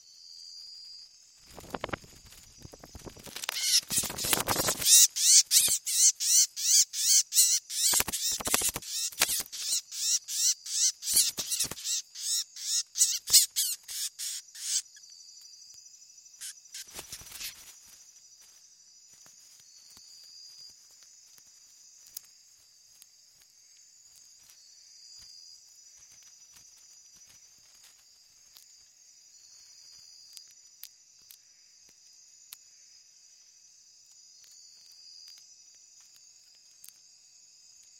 Just a sudden rush of air, a violent rustling, and then the unmistakable sounds of a fight thrash through my headphones: wings flapping, claws scraping, and the harsh cries of a prey animal fighting to survive.
Biologger recording of a bat attacking its protesting prey.